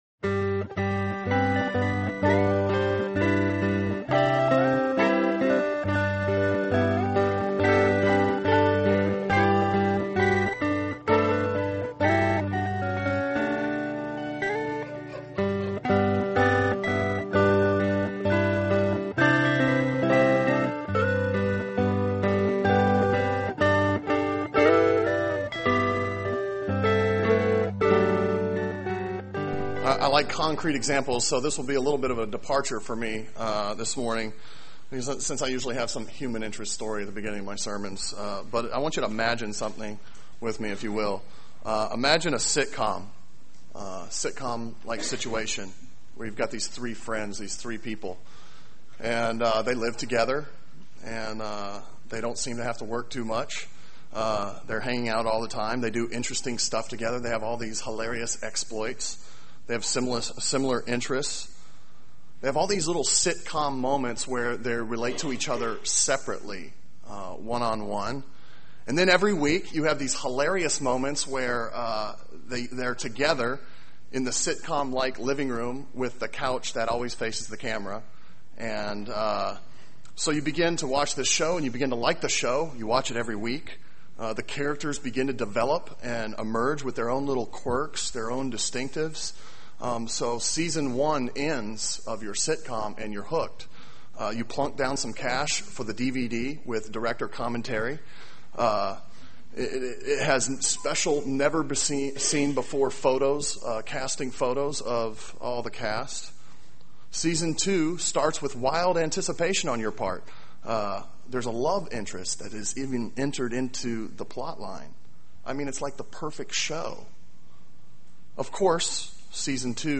Sermon Audio from Sunday